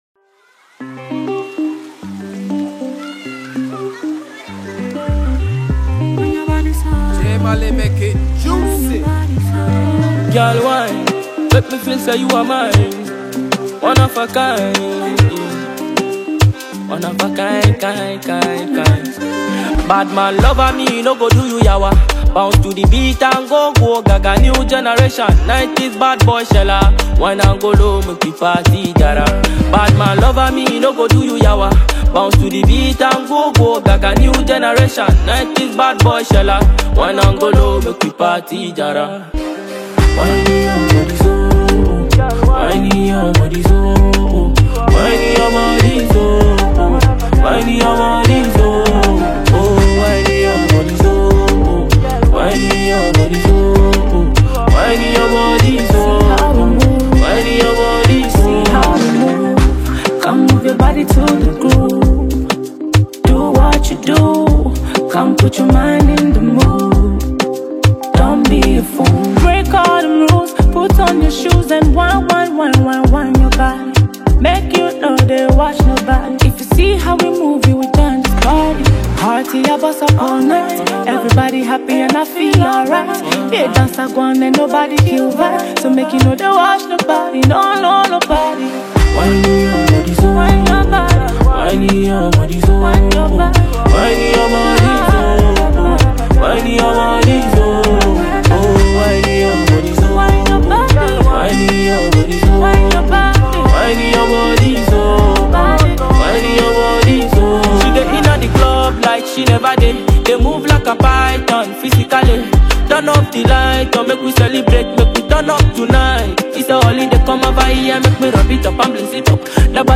Ghanaian dancehall